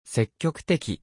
Japanese Unit Voice
Japanese unit responses.
And couldn't resist making some Japanese unit voices with it.